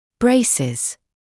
[‘breɪsɪz][‘брэйсиз]брекет-система со всеми ее элементами во рту у пациента, соответствует русскому общепринятому понятию «брекеты» (Она носит брекеты.